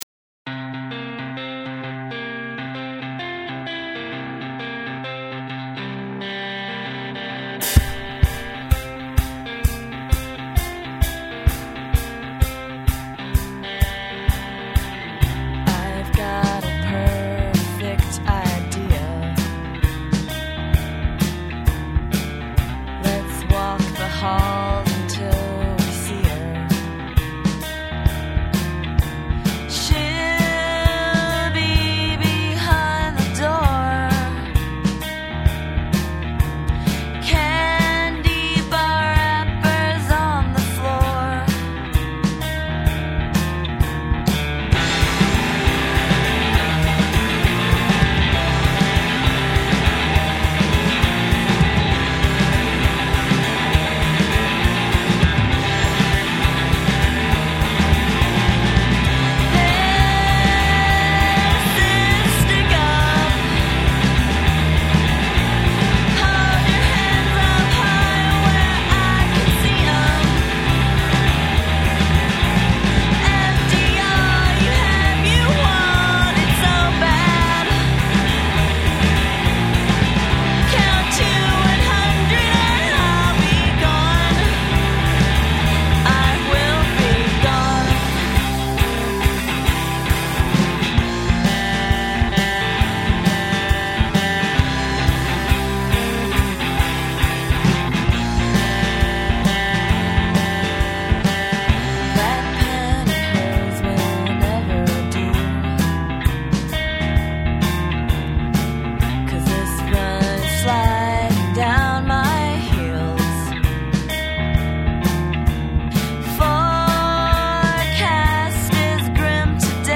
(3.5 mb) done right here in Chicago in '98!